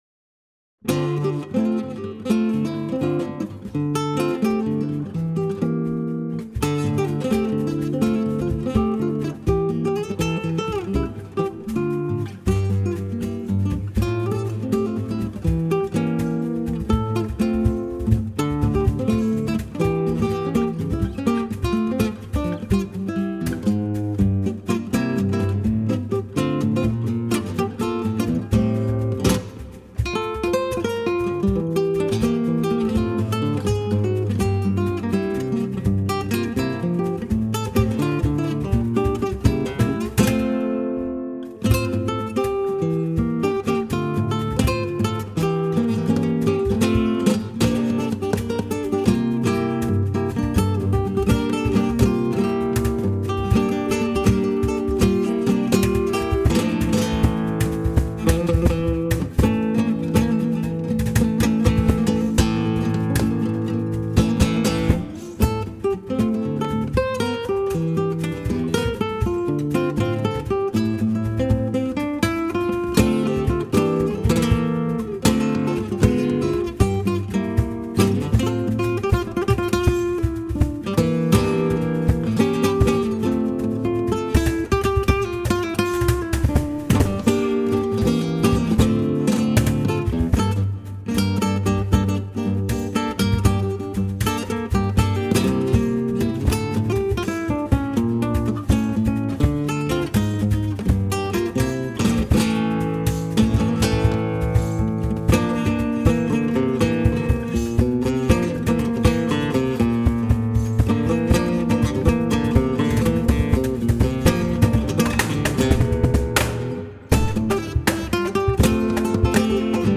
Gepflegter Gitarren-Jazz.
Im Duo mit Percussion